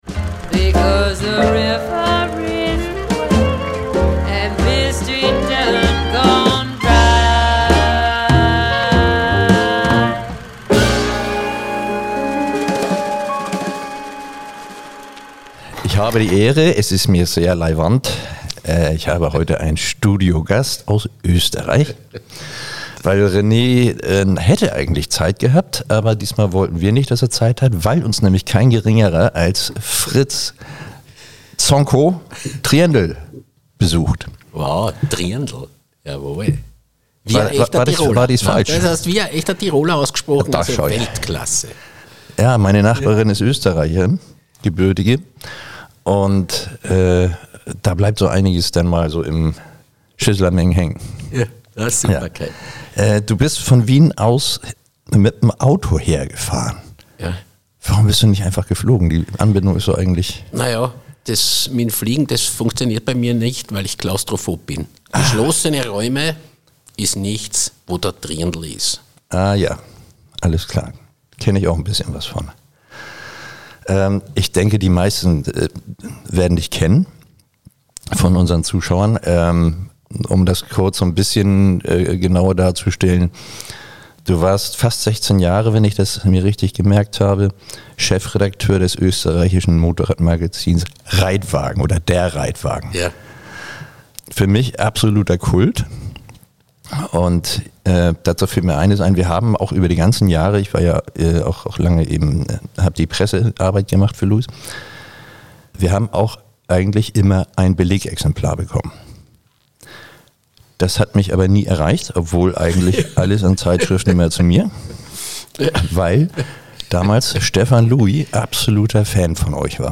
Der Kult-Journalist zu Gast! ~ Tante Louise – Der Motorradpodcast von Louis Podcast